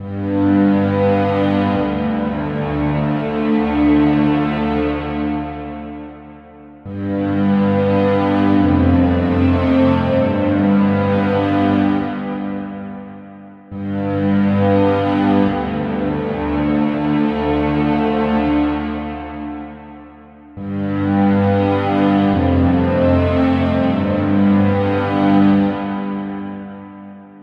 Cello
描述：A cinematic cello experience.
标签： 140 bpm Cinematic Loops Strings Loops 4.61 MB wav Key : Unknown FL Studio